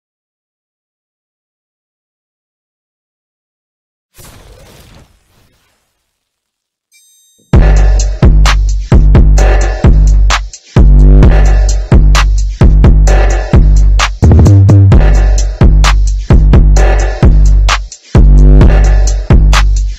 Beats